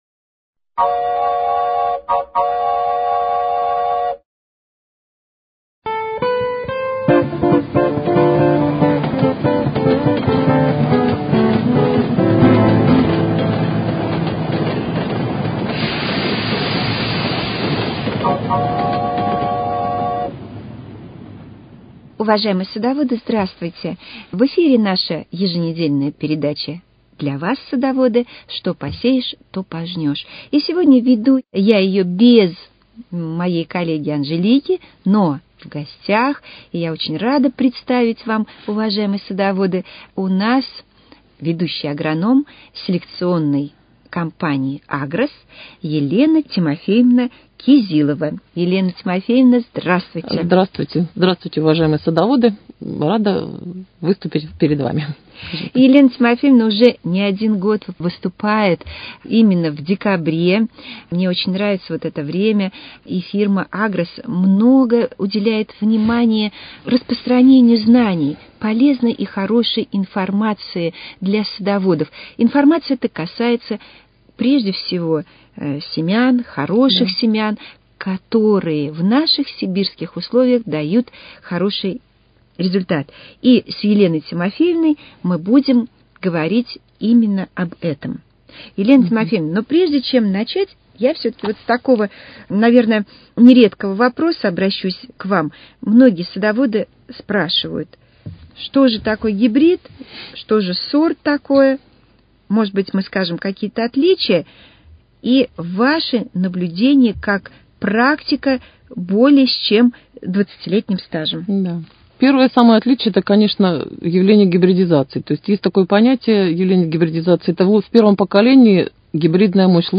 Что посеешь, то пожнешь: Беседа